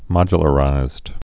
(mŏjə-lə-rīzd)